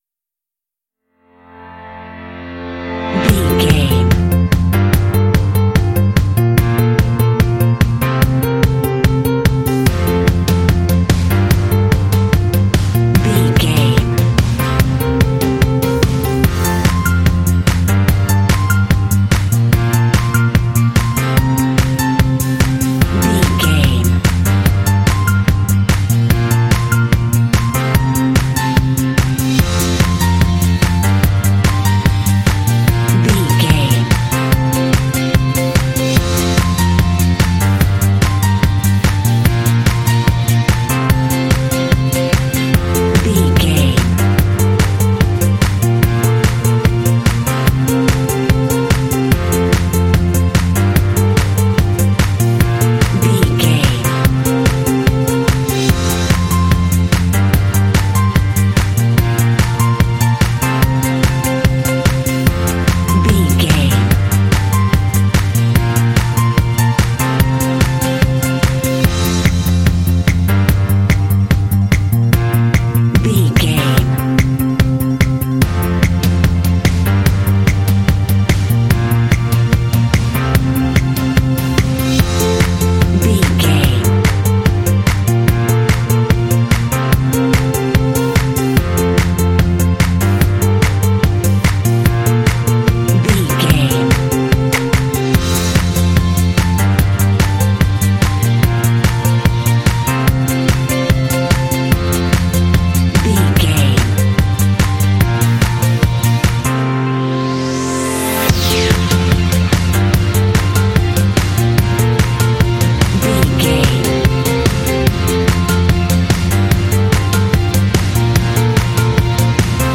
Bright and motivational music with a great uplifting spirit.
Ionian/Major
uplifting
bouncy
groovy
drums
strings
piano
electric guitar
bass guitar
rock
contemporary underscore
indie